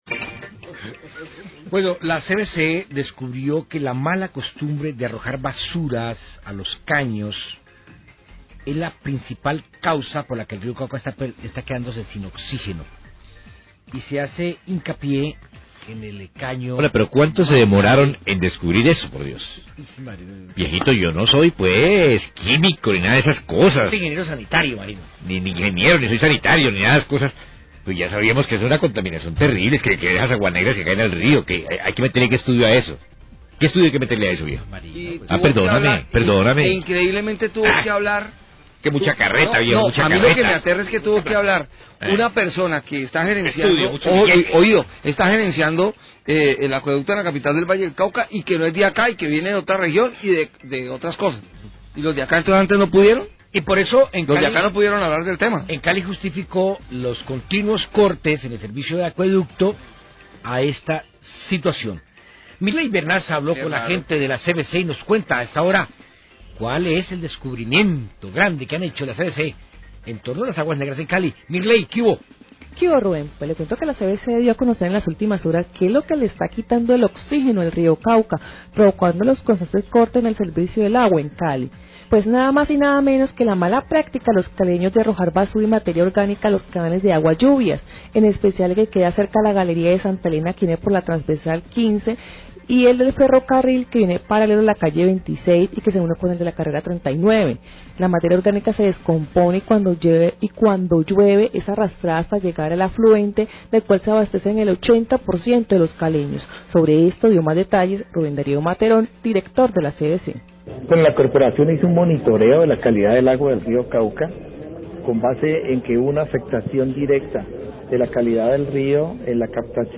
Radio
informe
A través de un estudio de las aguas del río Cauca, la Corporación Autónoma Regional del Valle del Cauca, CVC, determinó que los cortes reiterativos del suministro líquido en la ciudad se deben a malas prácticas de los caleños en el depósito de las basuras y a los asentamientos subnormales en la zona de ladera. Sobre este tema habla el director, Rubén Darío Materón. Periodistas opinan que las causas eran evidentes, no entienden por qué hasta ahora vienen a revelarlas.